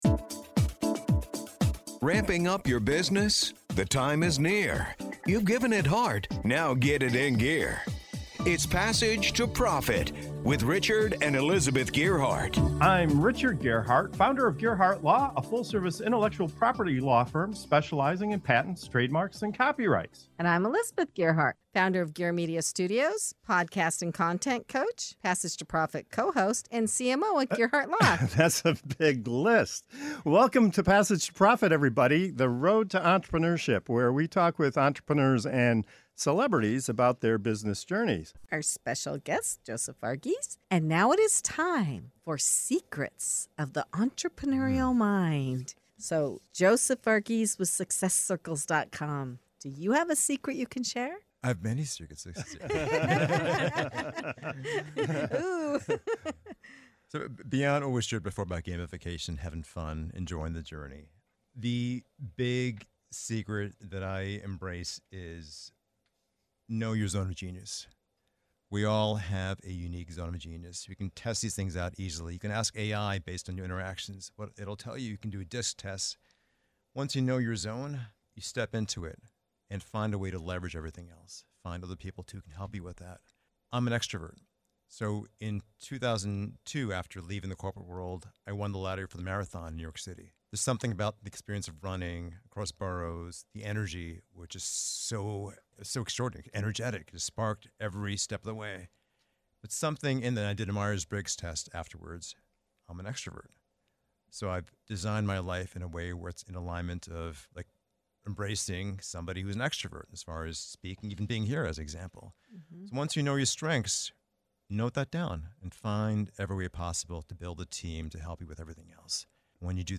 In this lively segment of "Secrets of the Entrepreneurial Mind" on Passage to Profit Show, our panel of entrepreneurs pulls back the curtain and shares the real secrets behind staying focused, productive, and energized while building a business. From discovering your unique zone of genius to creating daily routines that eliminate noise, embracing movement to avoid burnout, and even keeping a smart backup plan for when technology fails, each guest brings a powerful insight you can start using today. Packed with humor, honesty, and practical wisdom, this conversation offers a behind-the-scenes look at what truly fuels entrepreneurial success.